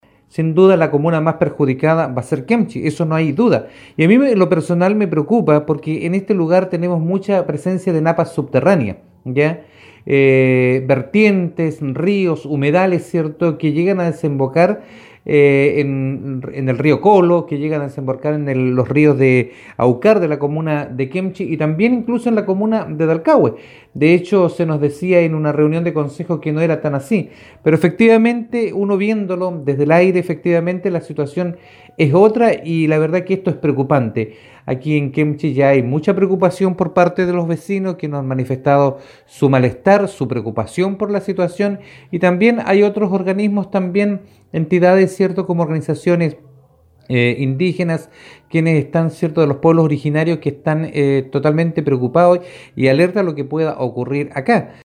Al respecto, manifestó su opinión en contrario a esta iniciativa, el concejal Javier Ugarte.